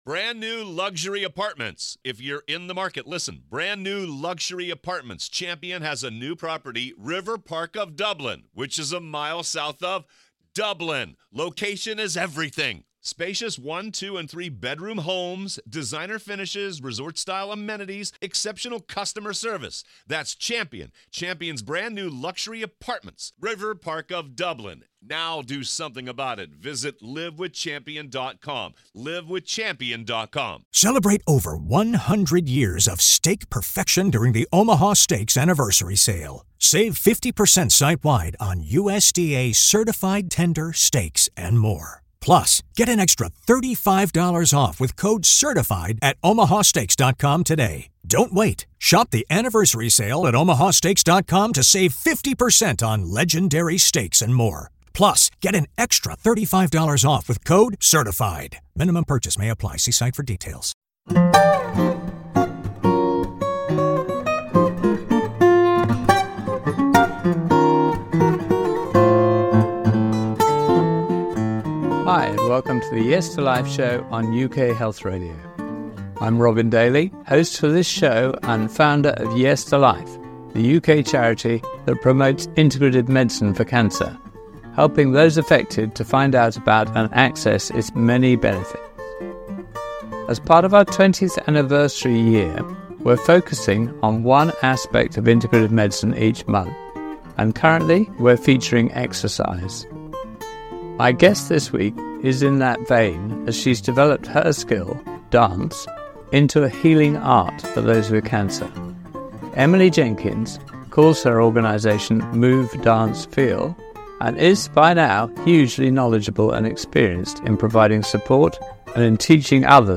talks to a fascinating mix of scientists, oncologists, practitioners and extraordinary survivors who have exceeded all expectations by thinking outside of ‘the box’.